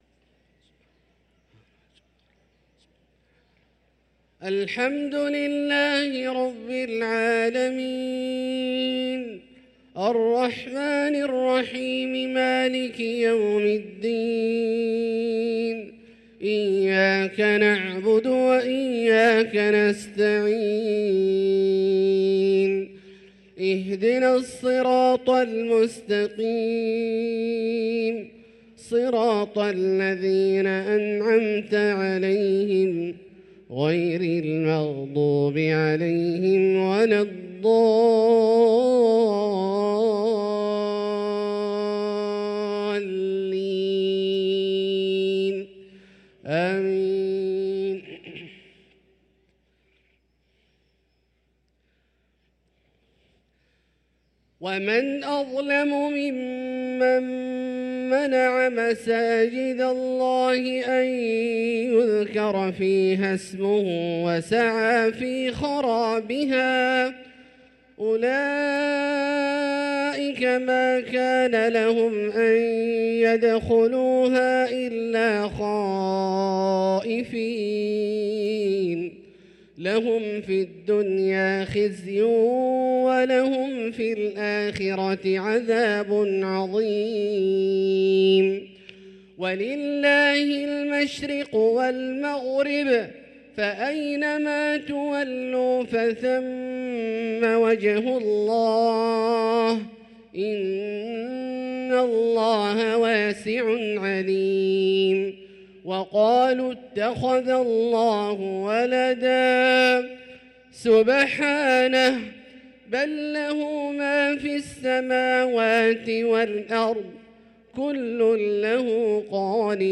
صلاة العشاء للقارئ عبدالله الجهني 17 جمادي الآخر 1445 هـ